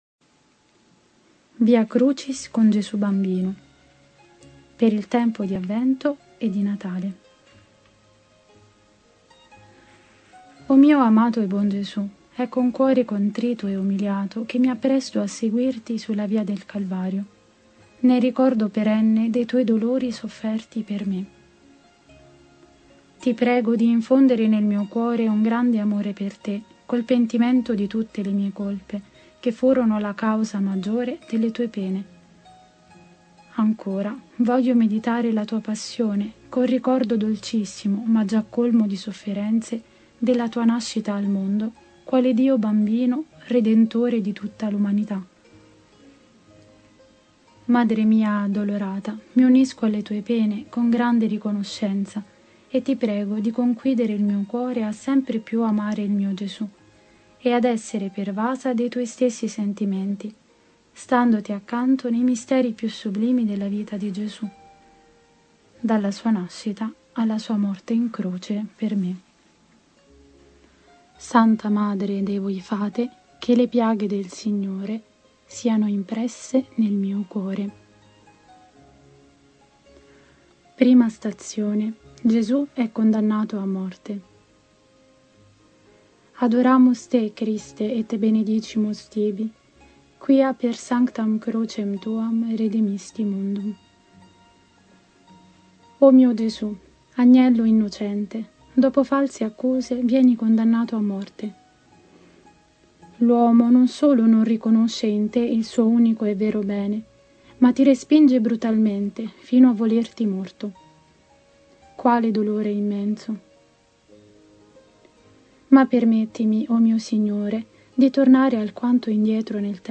Genere: Preghiere.